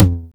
909 L Tom 2.wav